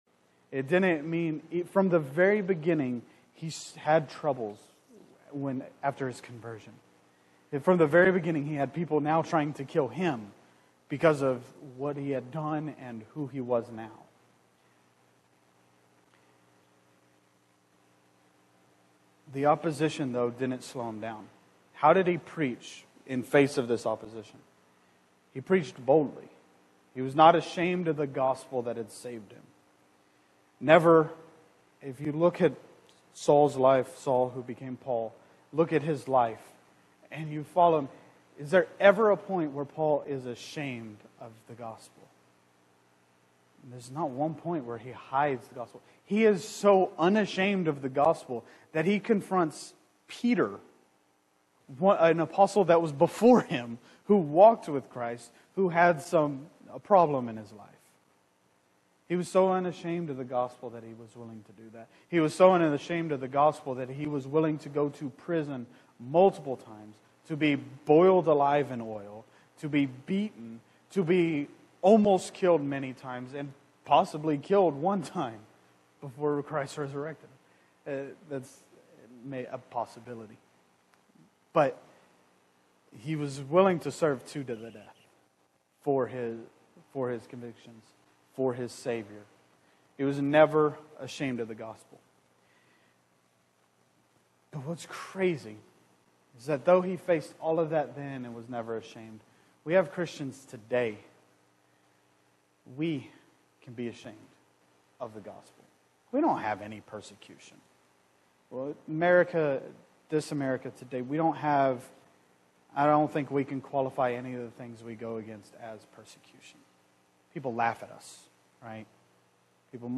The key text for this message is Acts 9:17-19. Note: This recording starts with the message in progress due to a power failure at the church.